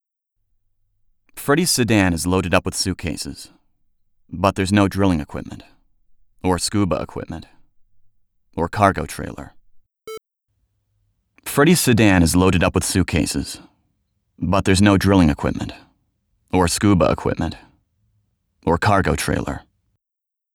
I am in the process of recording an audiobook using Logic Pro X. I recorded 2 chapters a little hot.
But there are these annoying headphone clicks that I can hear under some narration. I’ve attached one short passage to this post to give you a sample of what I’m fighting to clean.